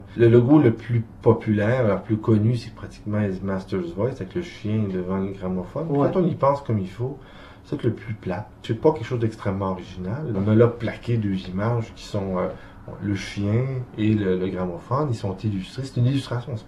extraits d´entrevues